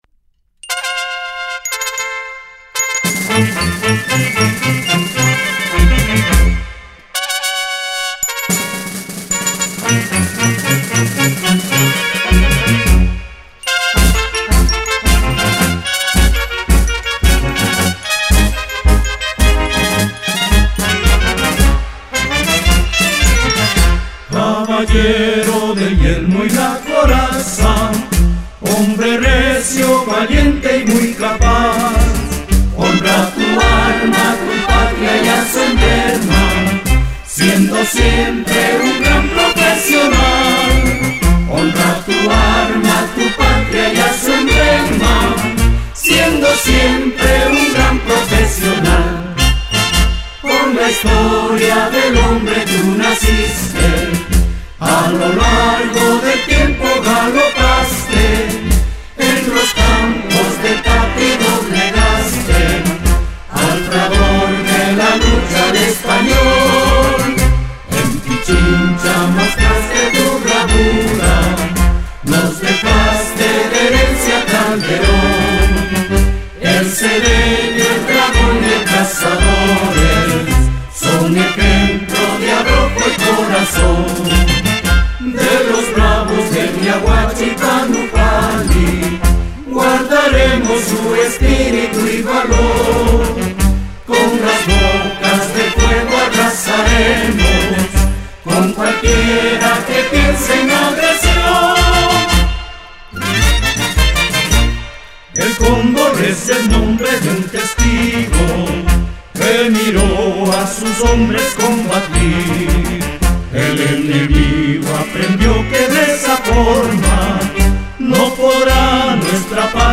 1.1 Himno de la Caballería Blindada (Cantado)
hcb-himno-de-la-caballeria-blindada-ecuatoriana-cantado-ok.mp3